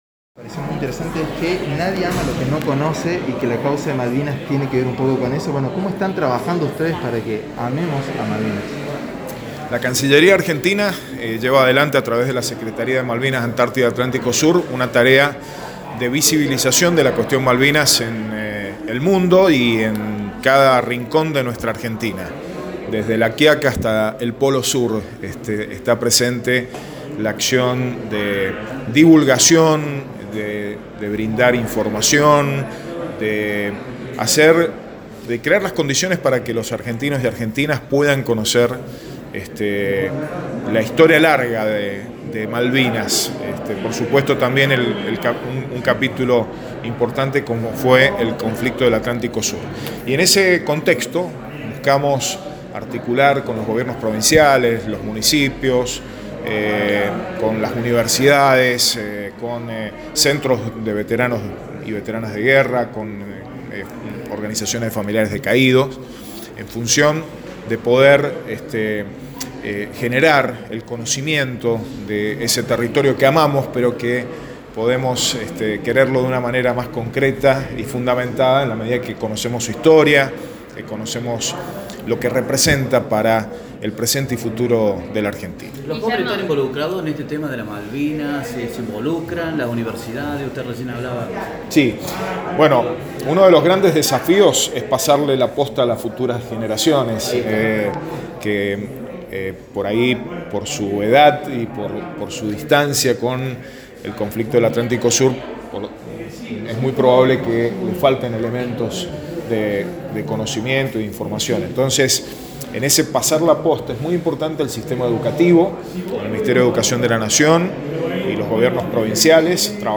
Escuche la palabra de Carmona en diálogo con la prensa villamariense en el inicio de esta nota: